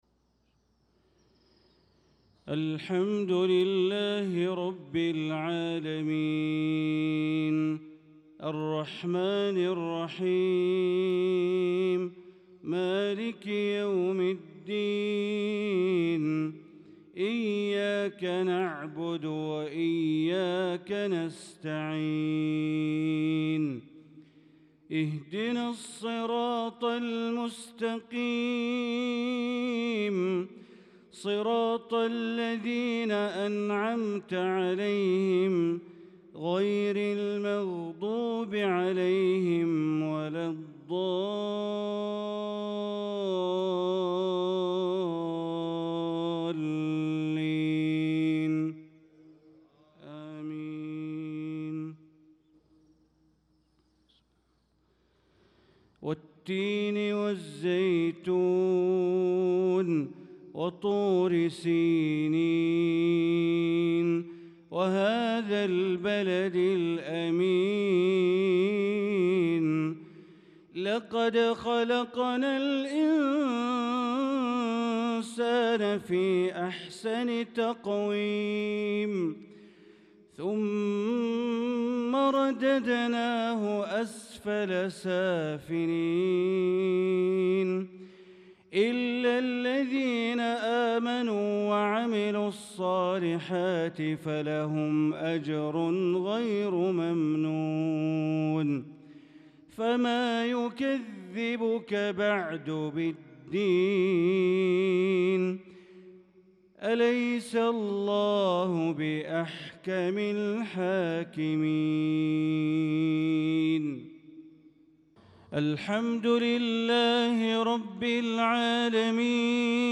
صلاة المغرب للقارئ بندر بليلة 3 ذو القعدة 1445 هـ
تِلَاوَات الْحَرَمَيْن .